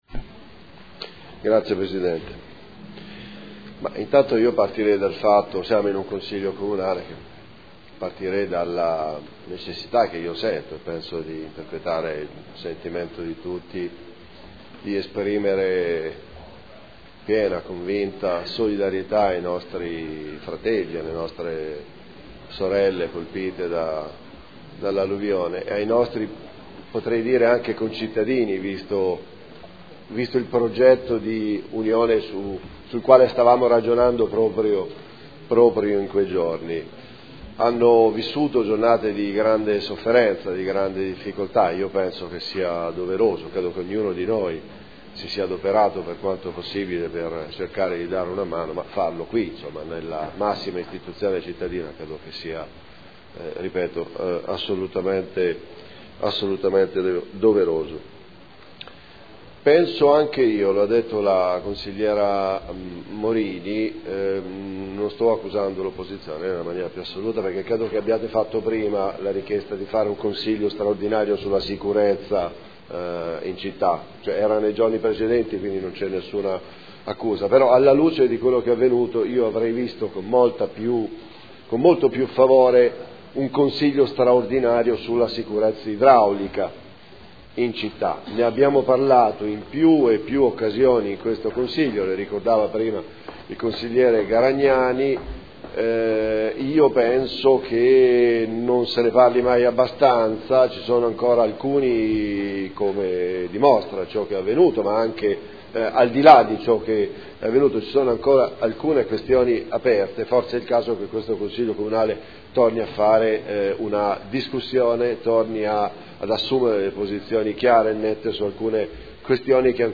Seduta del 30/01/2014. Dibattito su interrogazioni riguardanti l'esondazione del fiume Secchia.